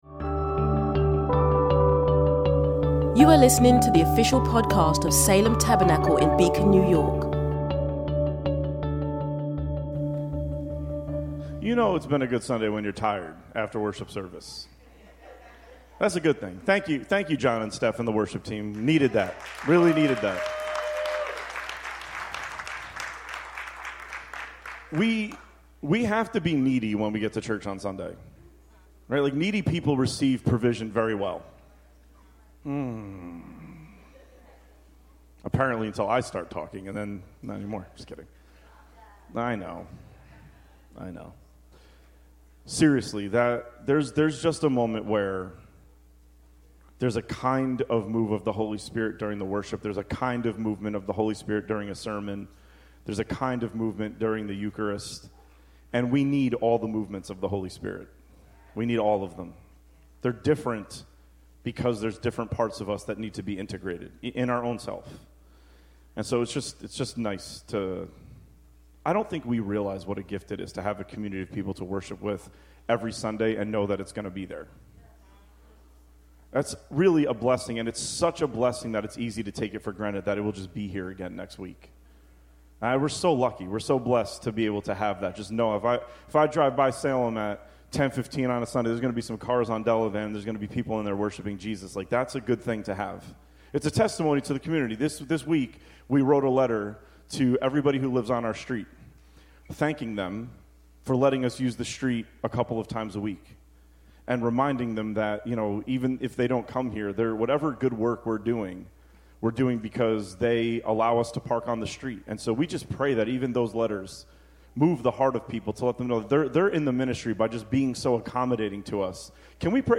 in Sermons